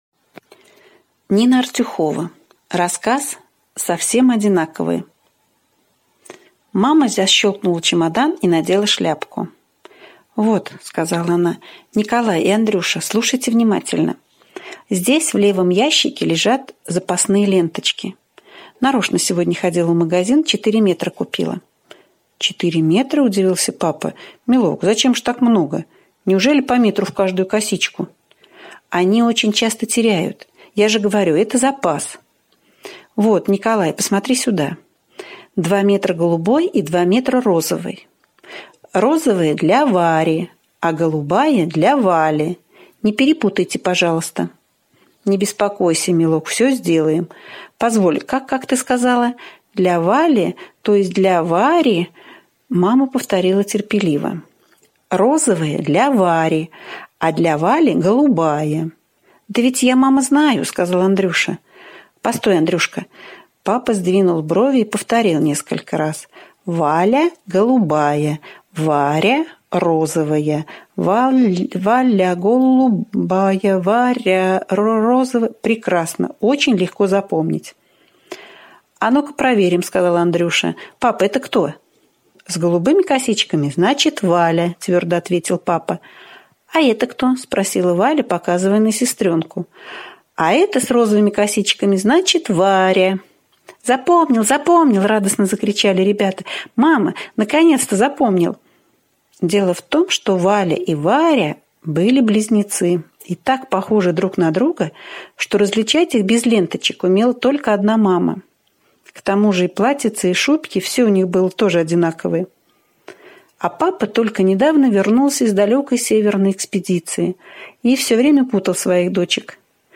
Совсем одинаковые — слушать аудиосказку Нина Артюхова бесплатно онлайн